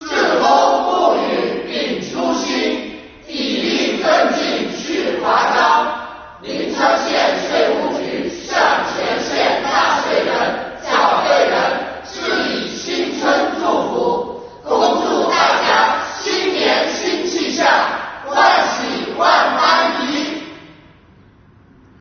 虎年春节将近，国家税务总局灵川县税务局为纳税人缴费人送上情意暖暖的新春祝福，祝福大家新年新气象，万喜万般宜。